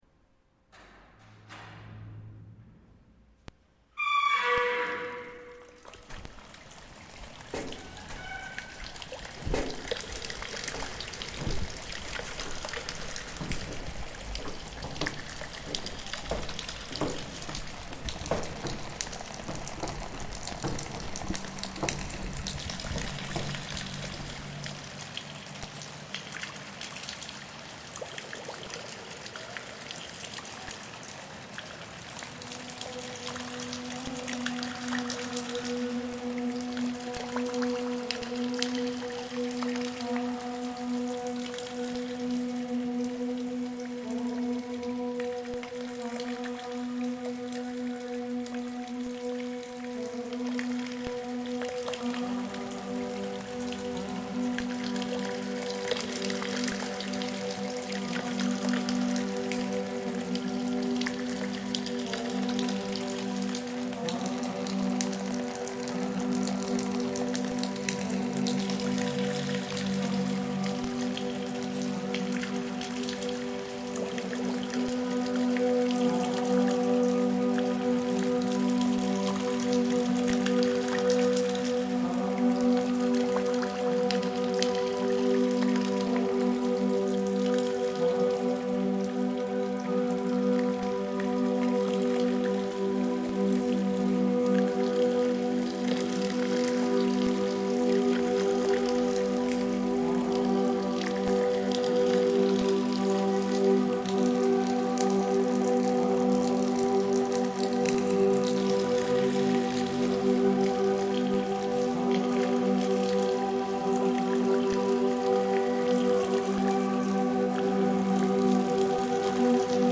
Light rain in Mostar reimagined by Cities and Memory.